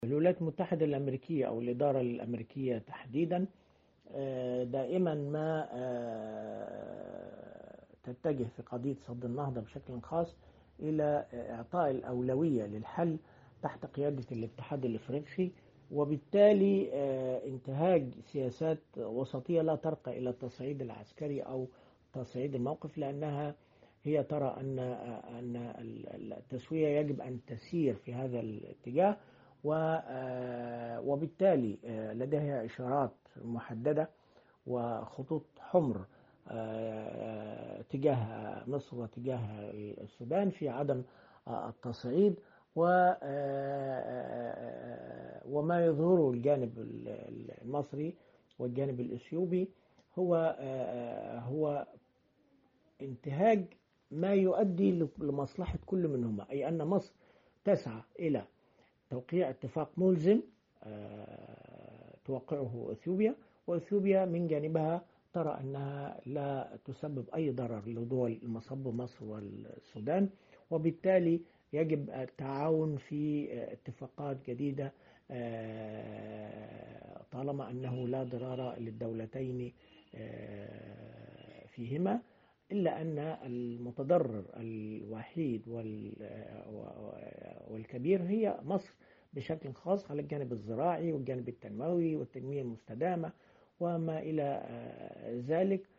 كاتب صحفي ومحلل سياسي